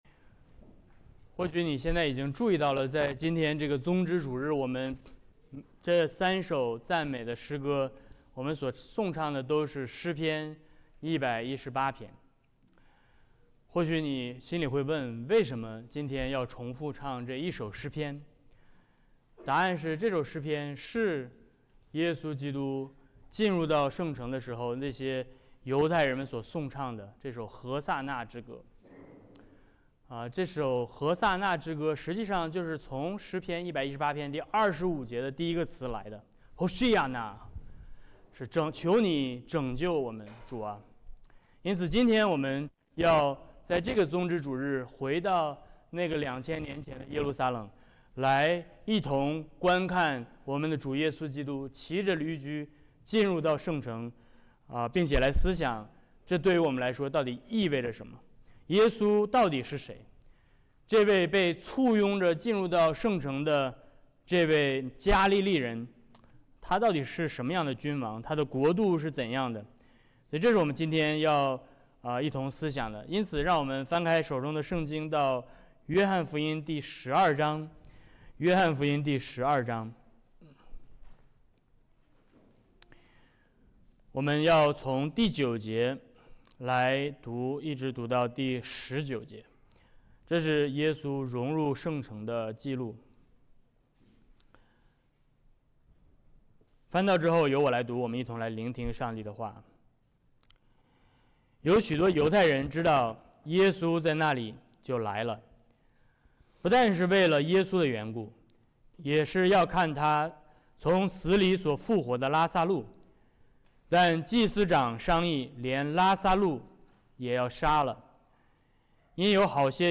节期讲道 Service Type: 主日讲道 2026年3月29日 | 主日12 圣道宣讲： 约翰福音 12:9-19 | 和散那之歌 « 多特信经｜65第五项教义